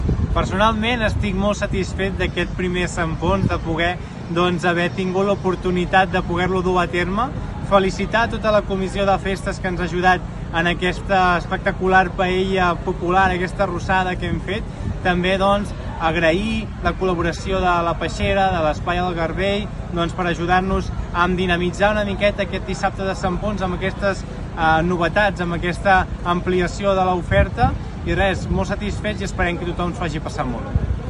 El regidor de Festes, Aniol Canals, es mostrava molt content per com s’havia desenvolupat la jornada i les noves activitats proposades.